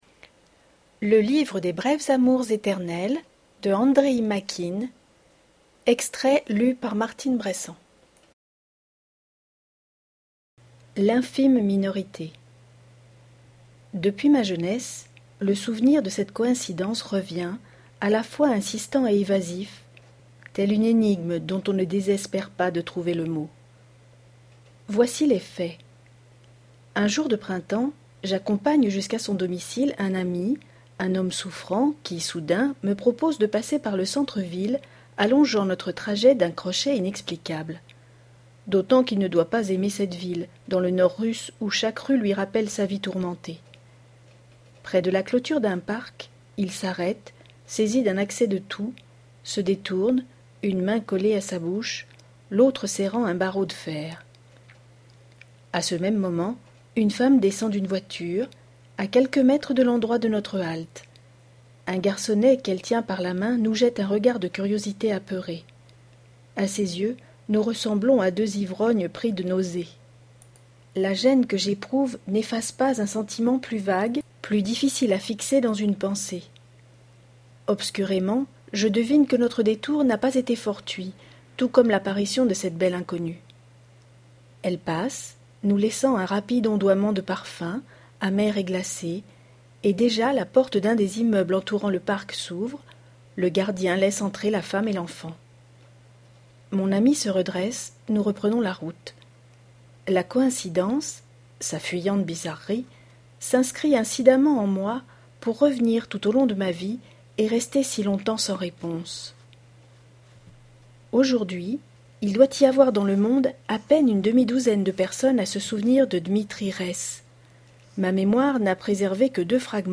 De mes yeux � vos oreilles est un site qui met � disposition des enregistrements audio de textes
ROMAN (extrait)